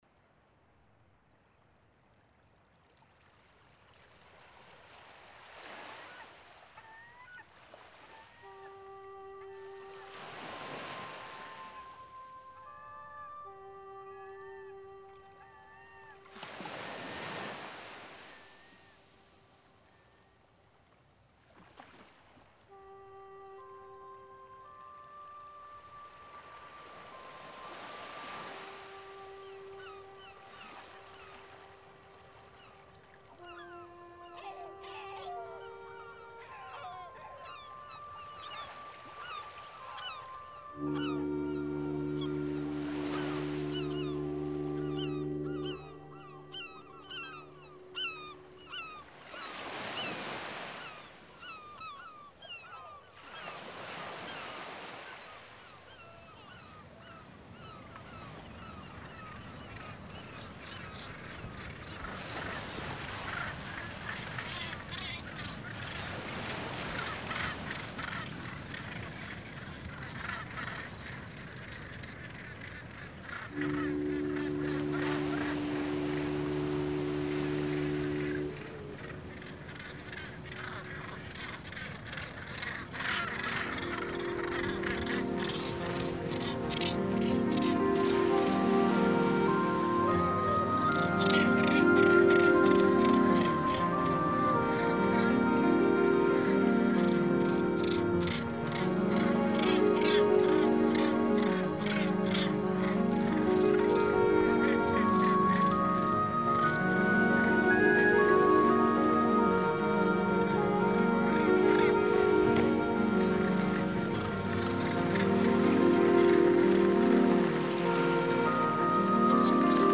Genre:NewAge